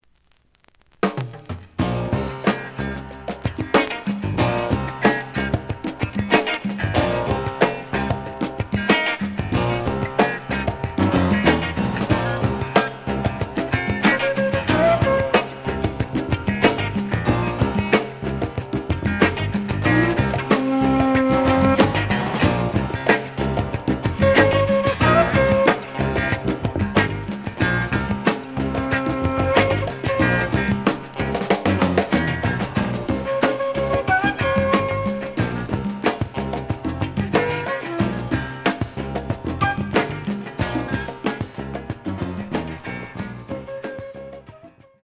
The audio sample is recorded from the actual item.
●Genre: Jazz Funk / Soul Jazz
Some click noise on middle of A side due to a bubble.